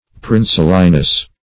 Search Result for " princeliness" : The Collaborative International Dictionary of English v.0.48: Princeliness \Prince"li*ness\, n. The quality of being princely; the state, manner, or dignity of a prince.